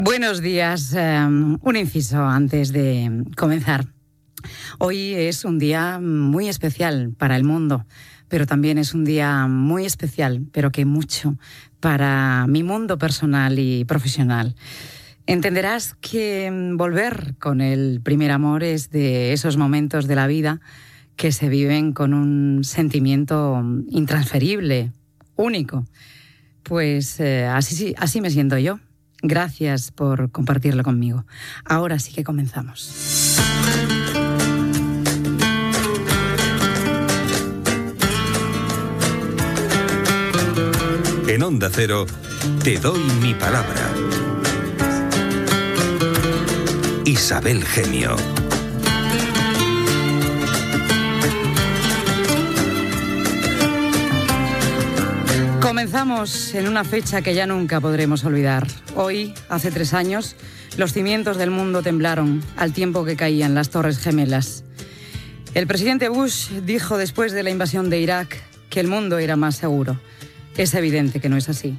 Salutació inicial, careta i record que feia tres anys dels atemptats de l'onze de setembre de 2001 als EE.UU.
Entreteniment